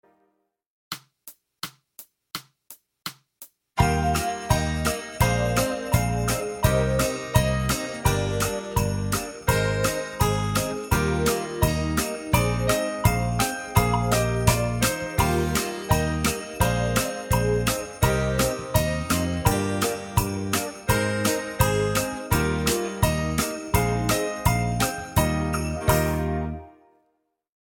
TROMBONE SOLO • ACCOMPAGNAMENTO BASE MP3
(tradizionale americano)